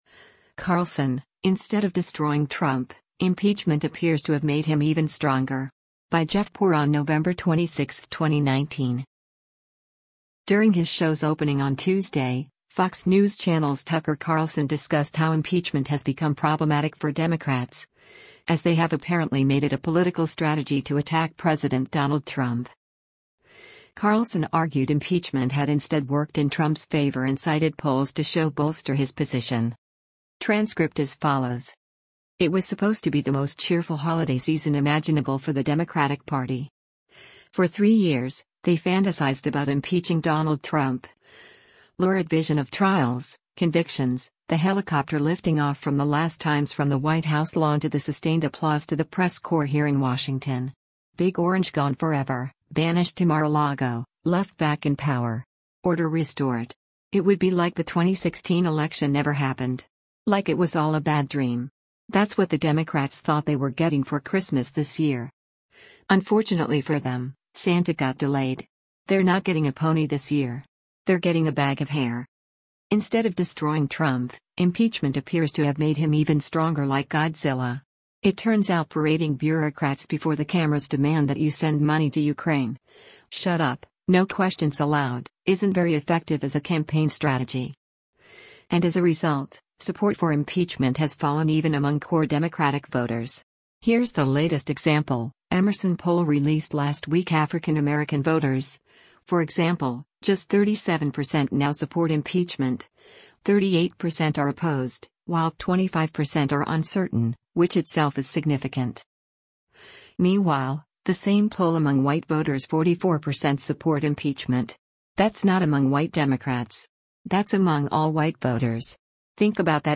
During his show’s opening on Tuesday, Fox News Channel’s Tucker Carlson discussed how impeachment has become problematic for Democrats, as they have apparently made it a political strategy to attack President Donald Trump.